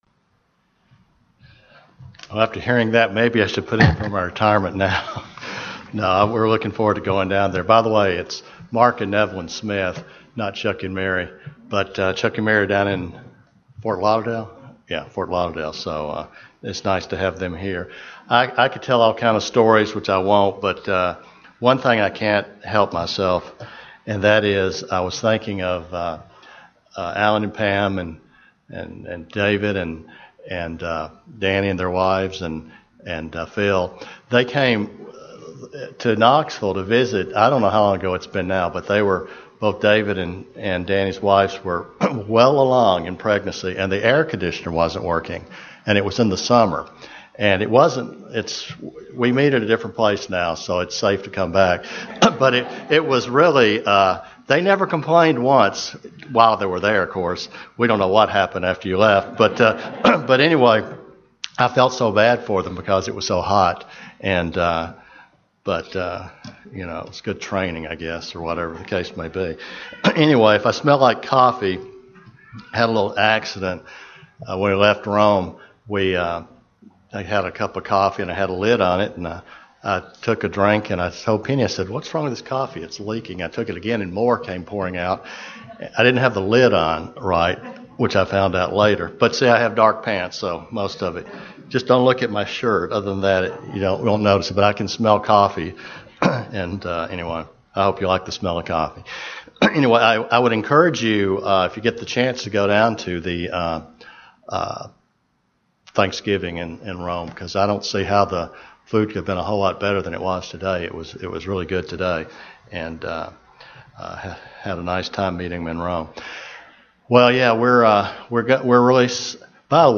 This sermon was given at the Jekyll Island, Georgia 2015 Feast site.